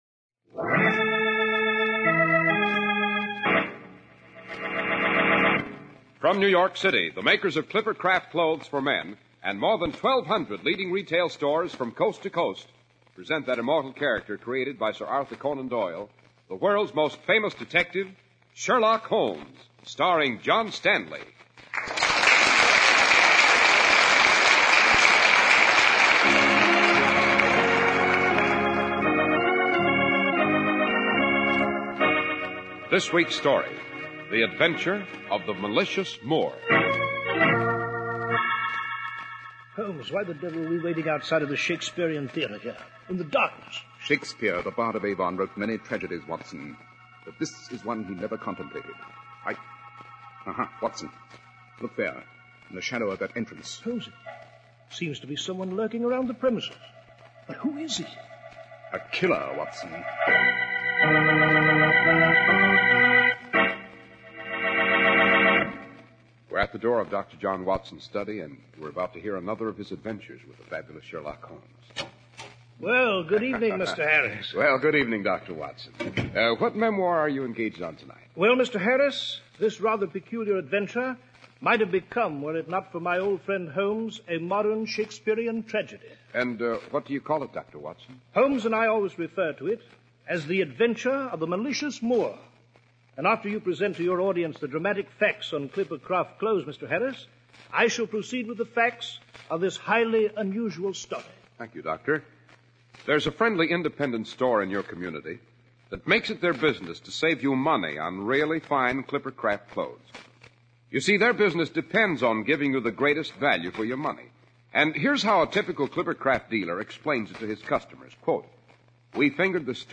Radio Show Drama with Sherlock Holmes - The Malicious Moor 1949